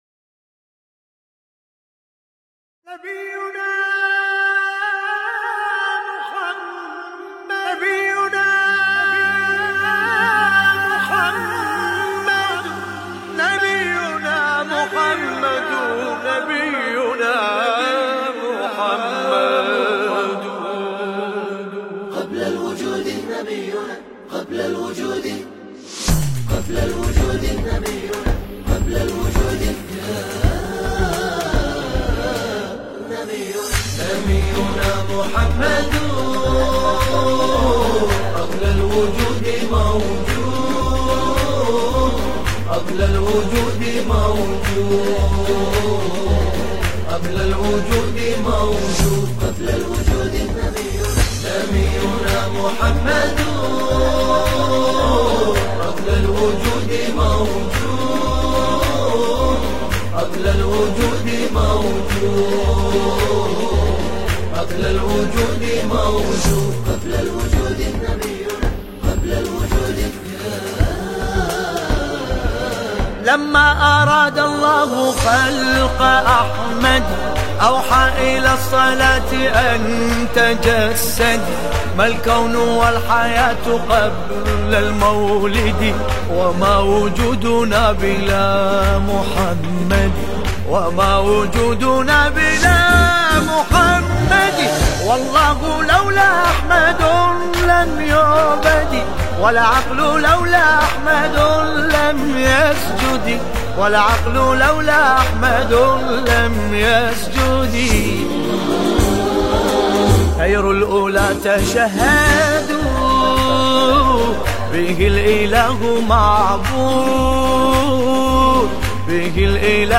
نماهنگ زیبای عربی
ویژه میلاد مسعود پیامبر اکرم صلی الله علیه و آله و سلم